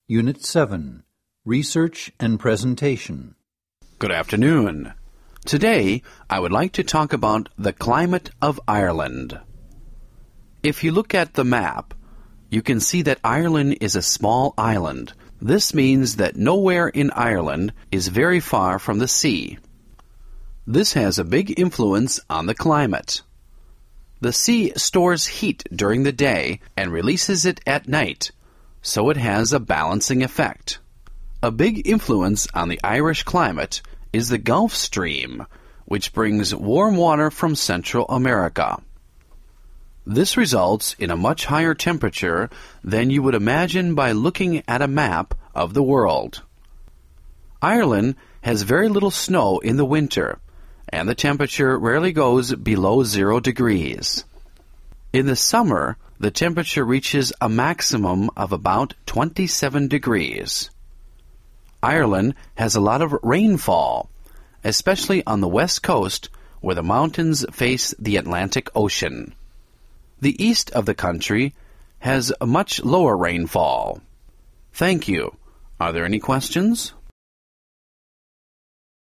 S:28  Research and Presentation
28_u07_ResearchPresentation.mp3